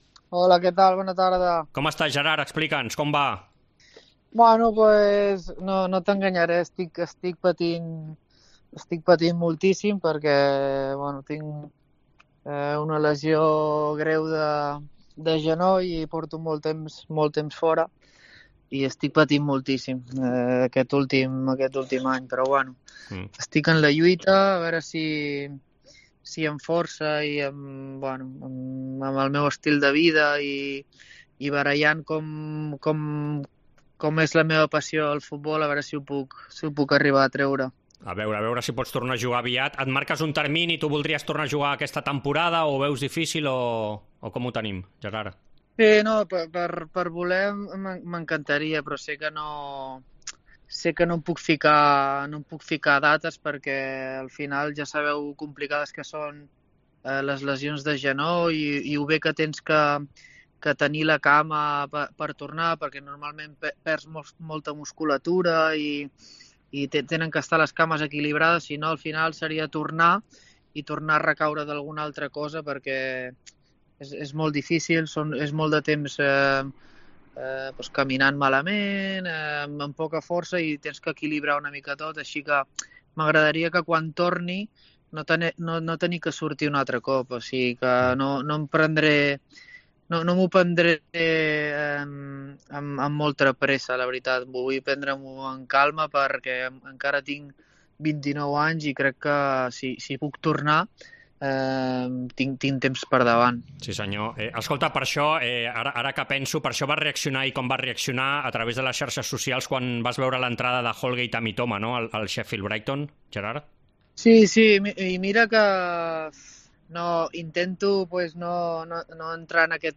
Entrevista Esports COPE